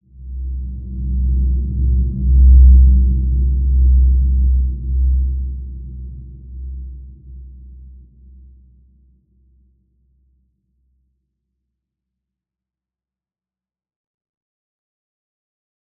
Large-Space-C2-mf.wav